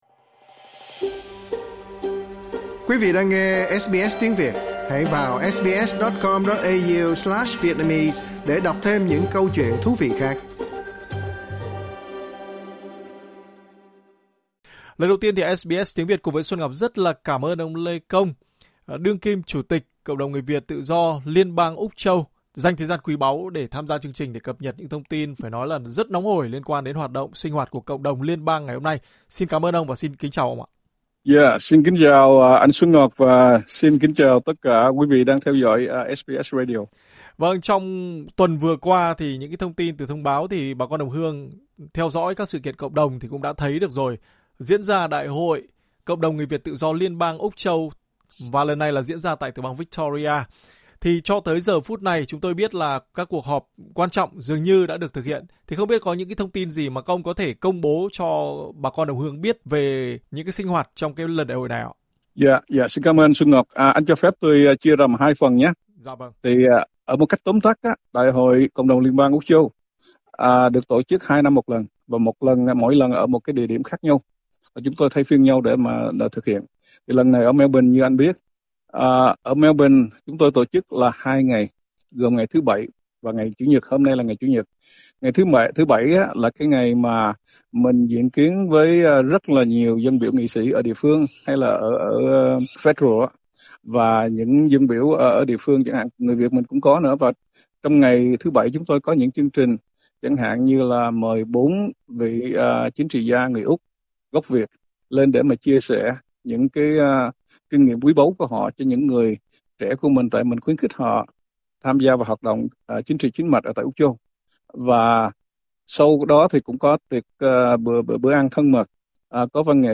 Trả lời phỏng vấn SBS Tiếng Việt